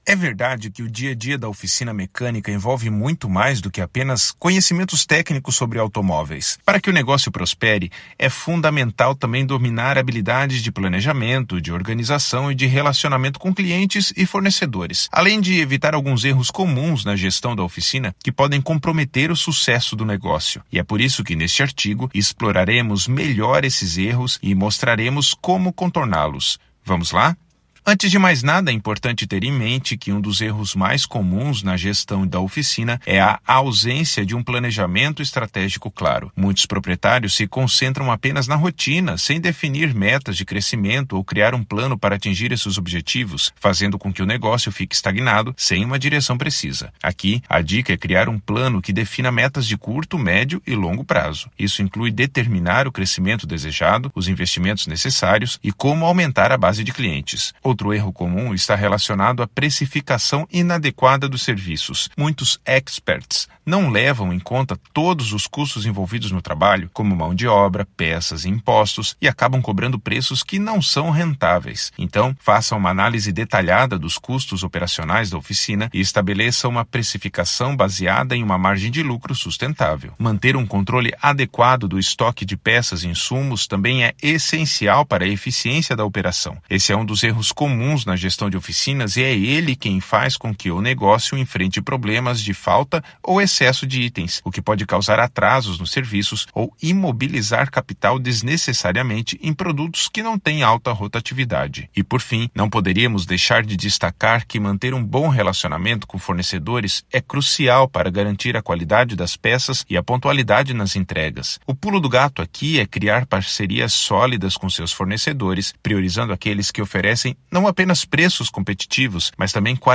Narracao-01-erros-na-oficina.mp3